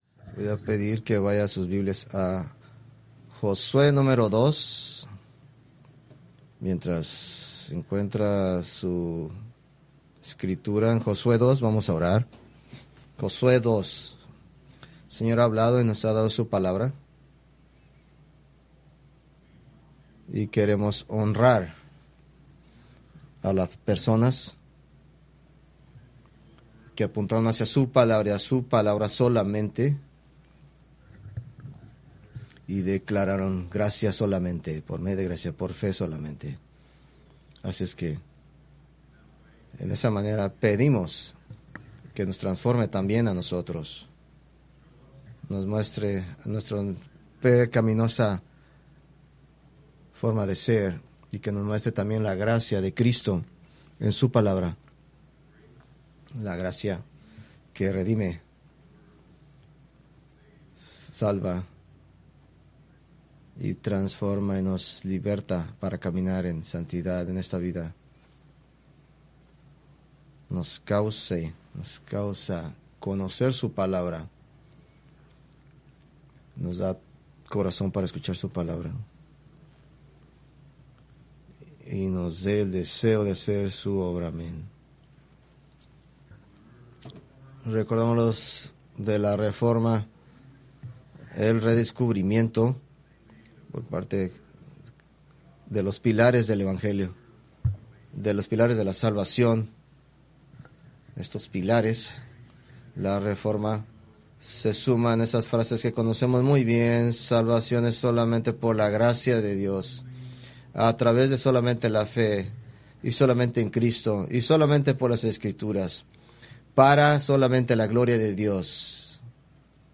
Preached October 26, 2025 from Joshua 2